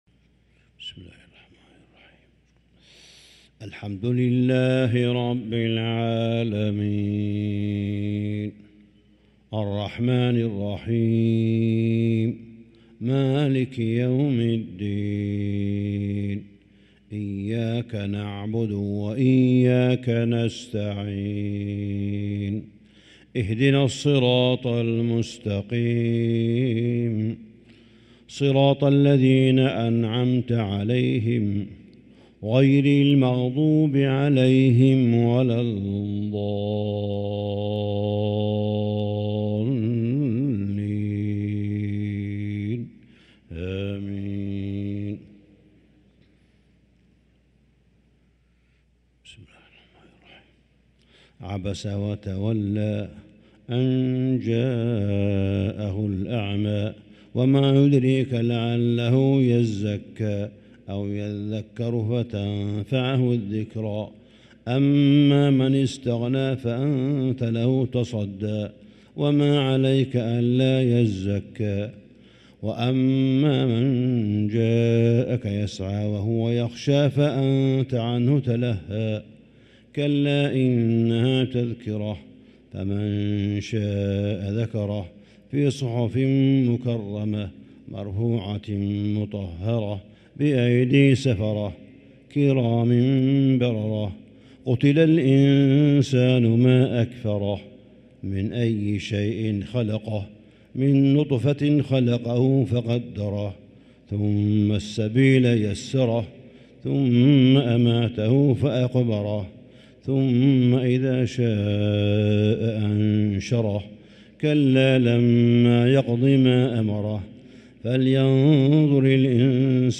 صلاة الفجر للقارئ صالح بن حميد 21 صفر 1445 هـ